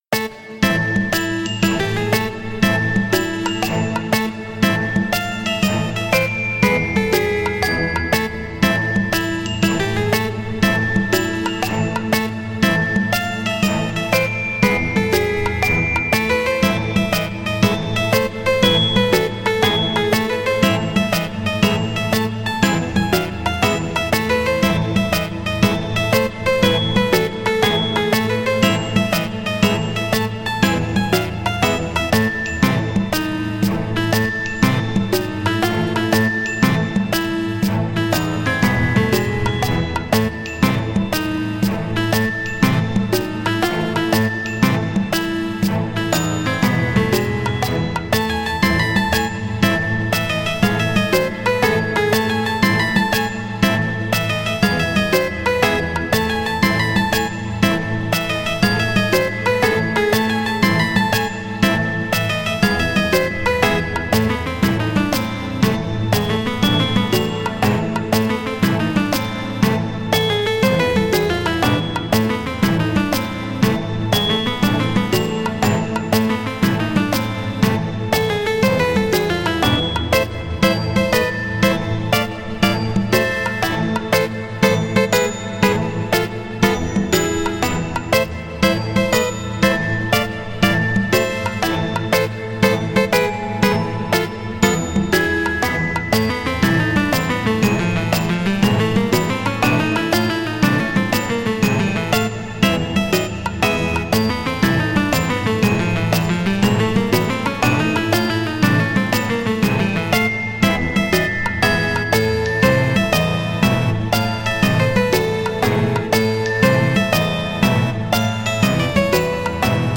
不思議で奇妙な雰囲気の曲です。【BPM120】